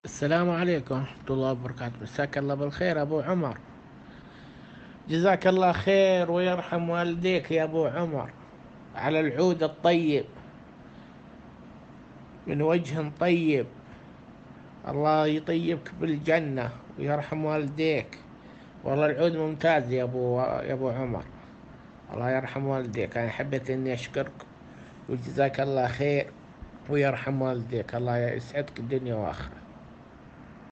التوصيات الصوتية من عملائنا
توصية صوتية 3